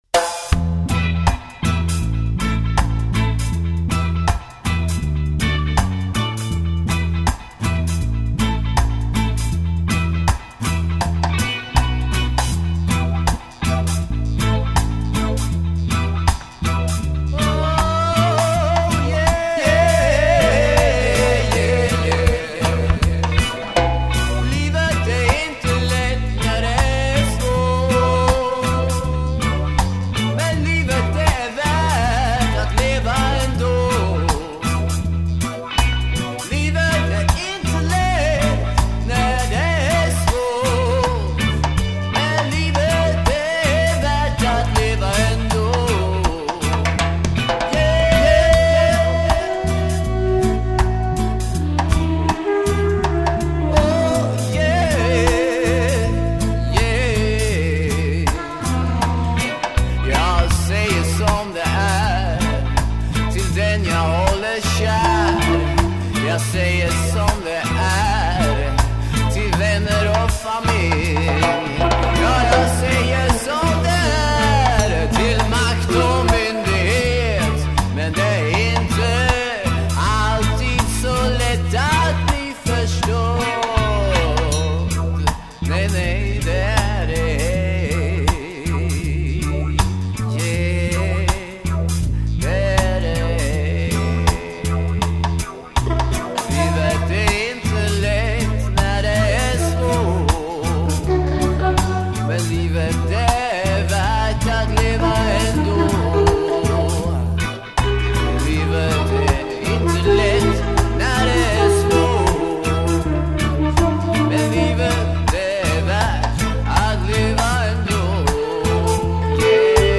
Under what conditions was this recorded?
Recorded on the north-side OF TOWN